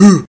startSignal.ogg